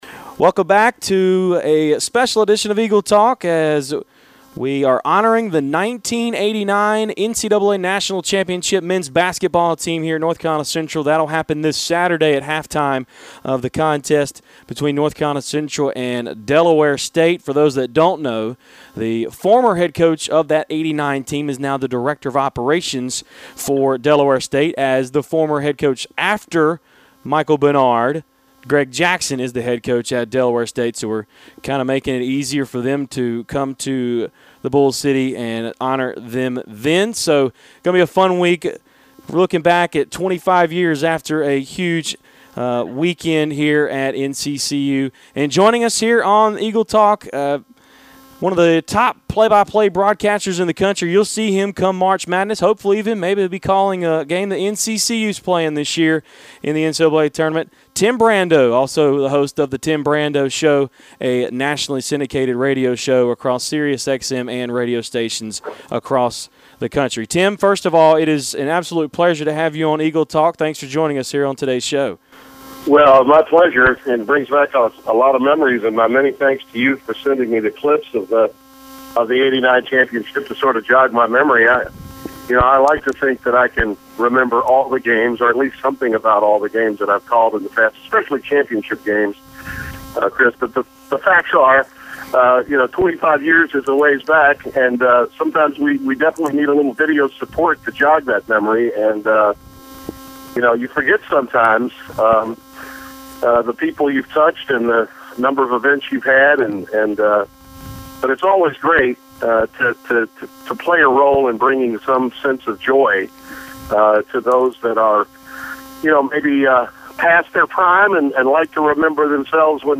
nationally syndicated radio host and TV play-by-play broadcaster Tim Brando about NCCU's 1989 NCAA Division II National Championship team
TIM_BRANDO_INTERVIEW_EAGLE_TALK.mp3